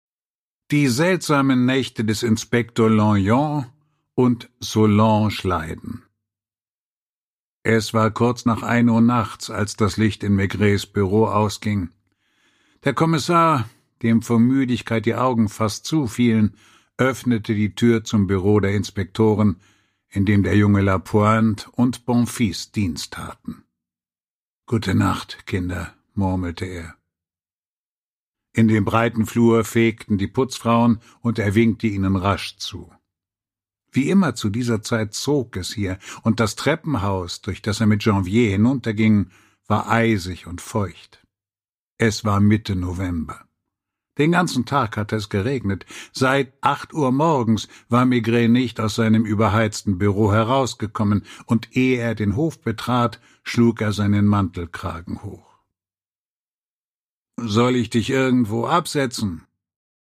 Produkttyp: Hörbuch-Download
Gelesen von: Walter Kreye